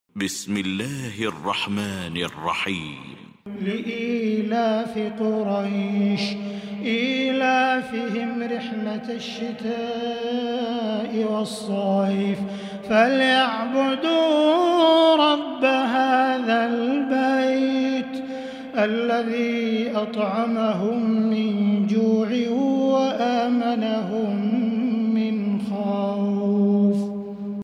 المكان: المسجد الحرام الشيخ: سعود الشريم سعود الشريم قريش